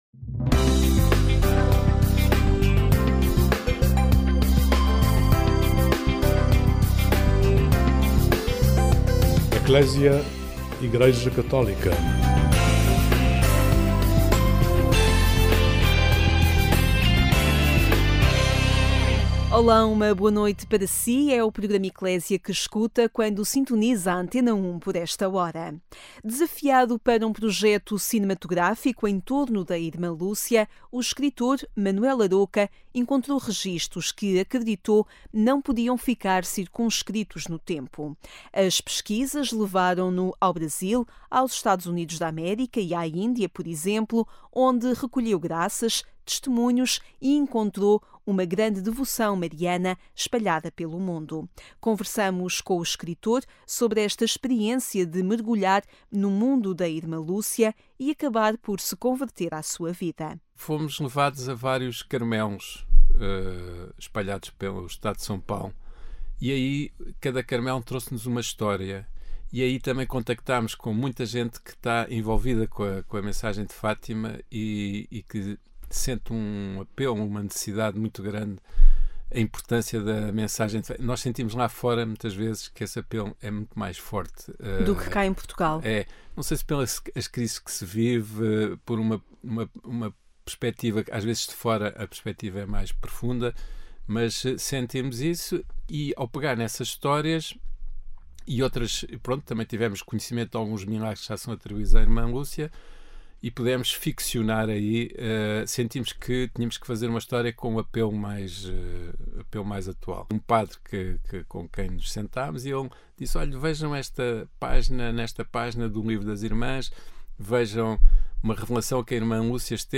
Desafiado para um projeto cinematográfico em torno da irmã Lúcia o escritor Manuel Arouca encontrou registos que, acredita, não podiam ficar circunscritos ao tempo. As pesquisas levaram-no ao Brasil, Estados Unidos da América, Índia e Oriente, recolhendo graças, testemunhos e uma grande devoção mariana espalhada pelo mundo. Conversamos com o escritor sobre esta experiência de mergulhar no mundo da Irmã Lúcia e acabar por se converter à sua vida.